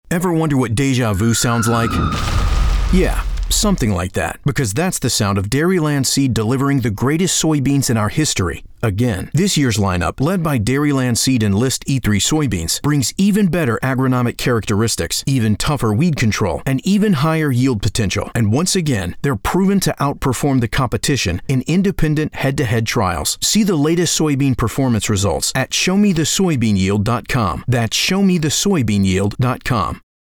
RADIO
In keeping with the concept, we sought to leap out of the sea of sameness with disruptive sound effects and unapologetic confidence in our seed performance.